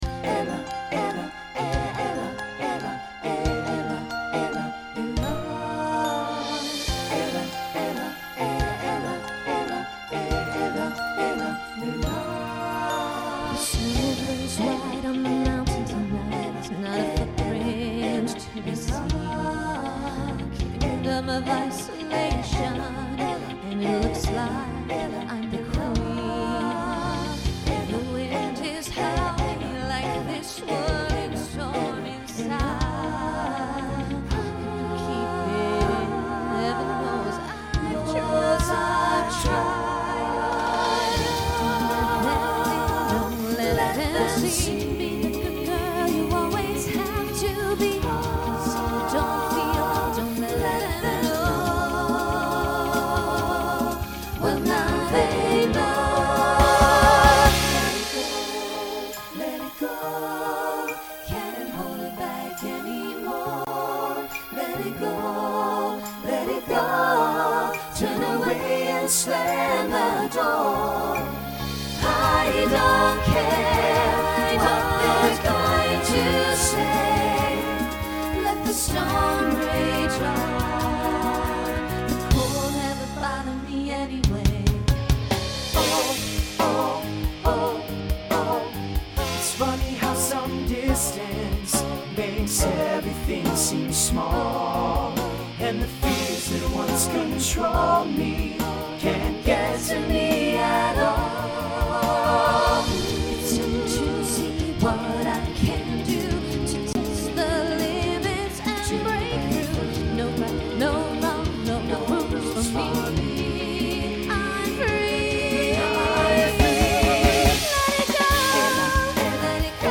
Voicing SATB
Pop/Dance Decade 2010s Show Function Ballad , Mid-tempo